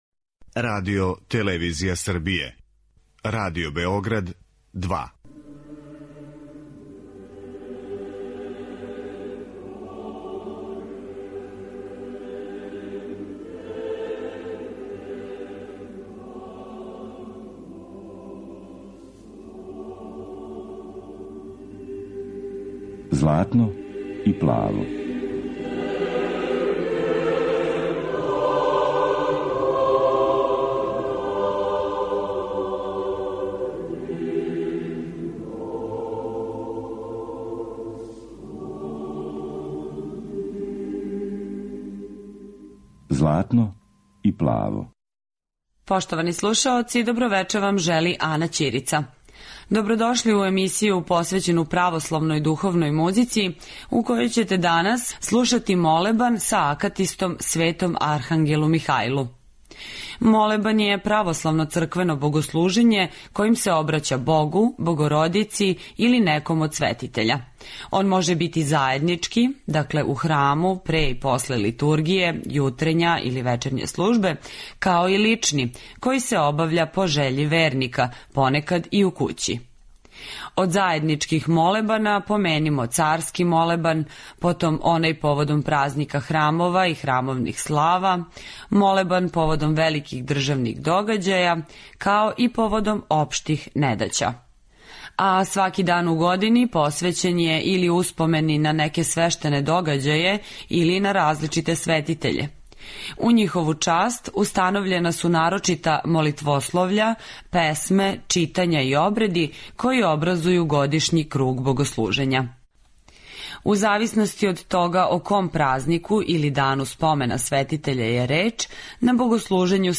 Молебан и акатист
Православна духовна музика